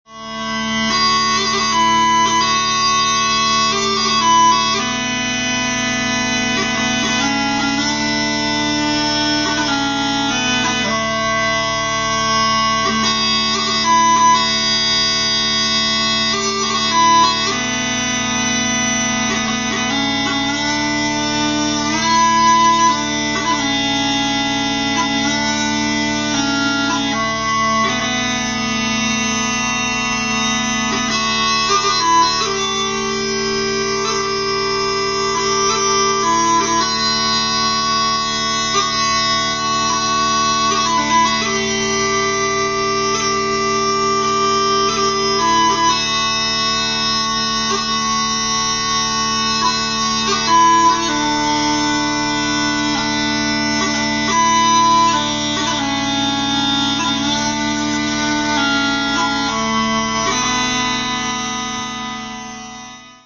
The internal design both of the stock and of the chanter has been modified in a way which now gives the chanter much more resonance and projection.
Here are some tunes played on them: